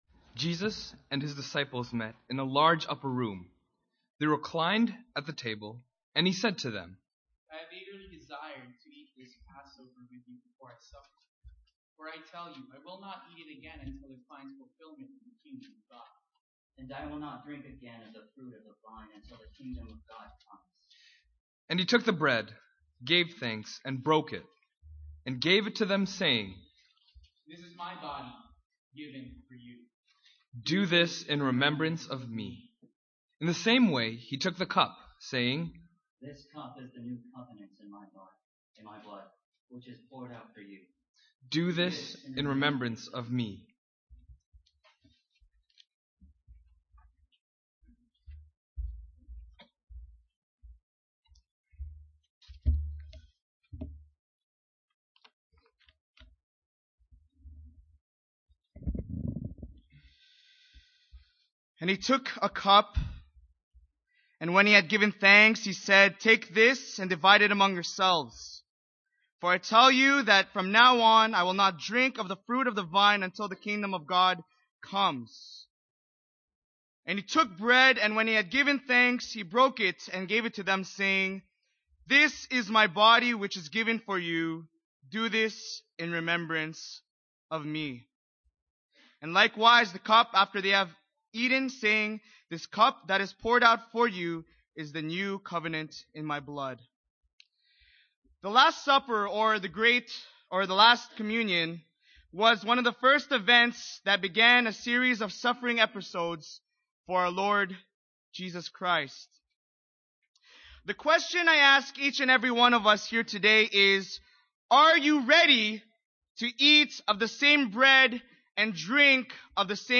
Mar-25-Journey-with-Jesus-to-the-Cross-Good-friday-ser.mp3